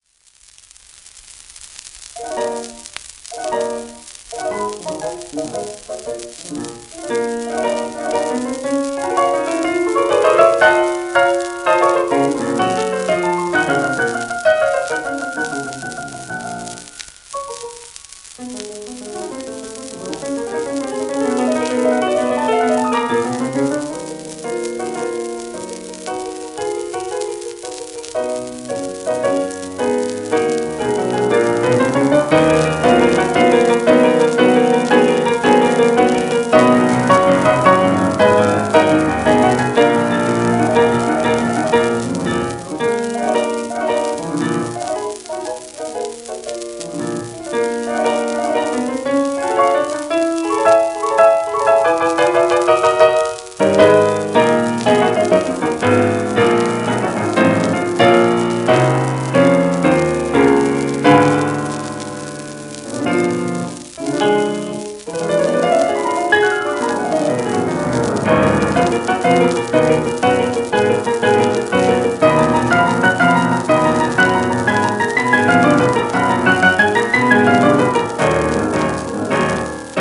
1932年録音